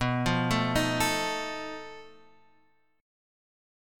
B7b5 chord